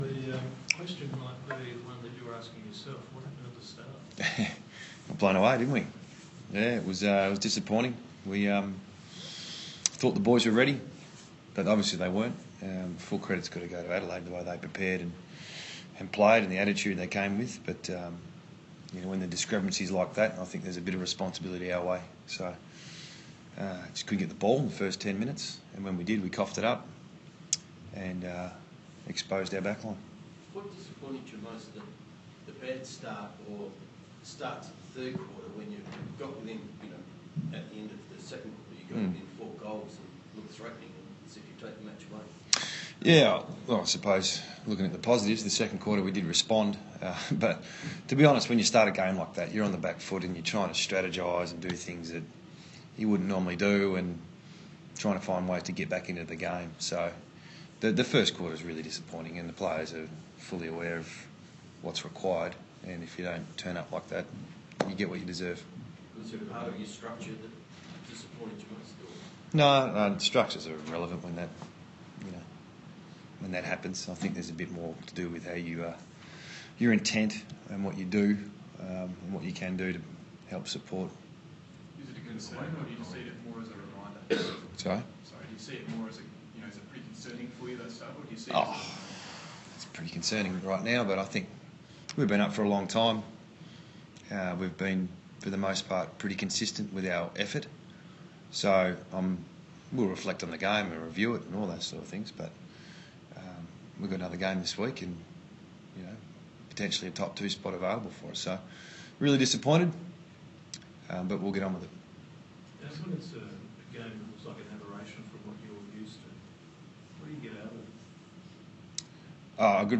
West Coast Eagles coach Adam Simpson speaks after their 57-point loss to the Adelaide Crows